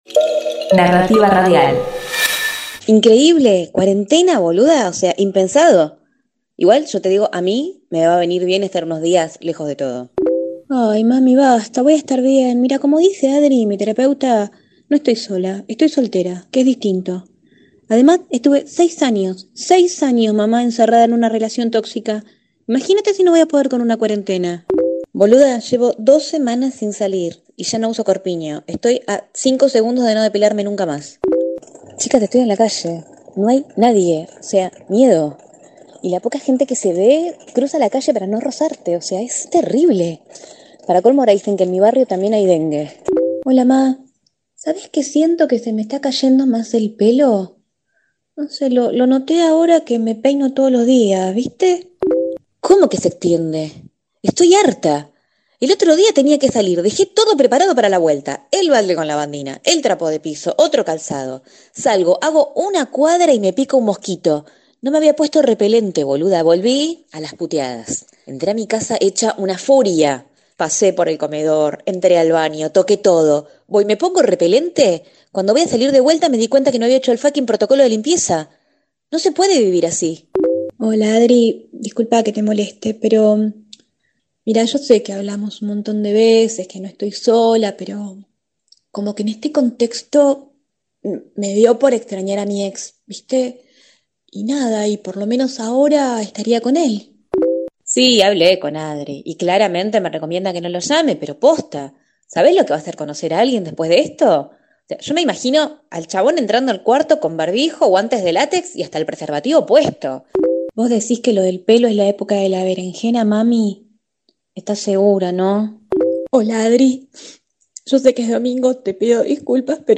Proyecto urgente: Ficción por mensajes de voz
Ya que la imaginación es la locación imprescindible para poder llevarse a cabo, nos parece un buen desafío -y entrenamiento tanto en términos de escritura como de interpretación- tratar de contar mediante la limitación: el sólo recurso de la grabadora de voz en los teléfonos móviles.